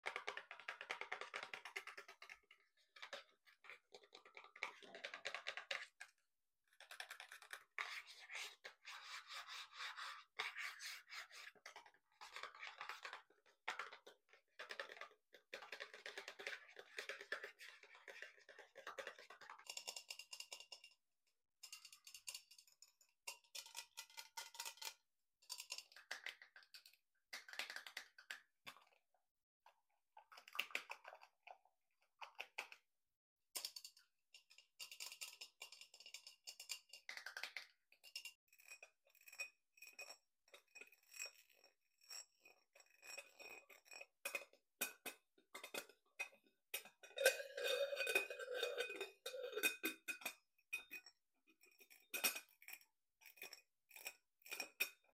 Asmr on 3 different objects sound effects free download